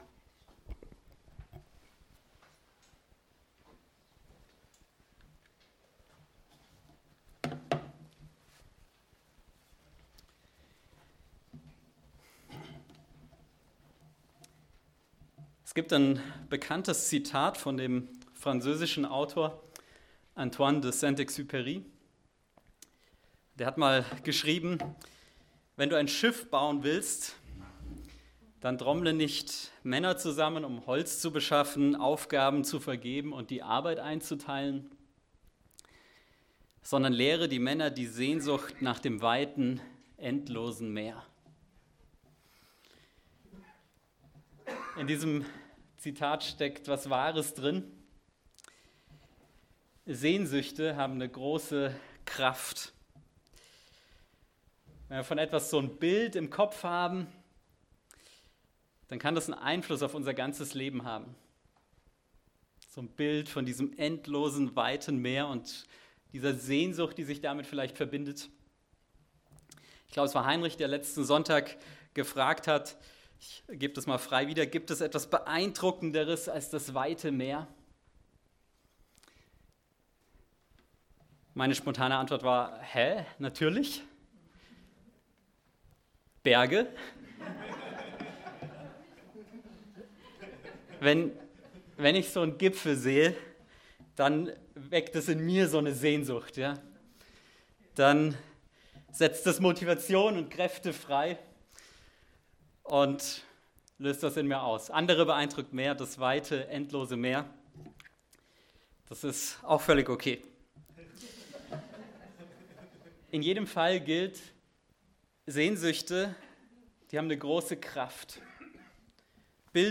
Aus der Predigtreihe: "Gottes Königreich"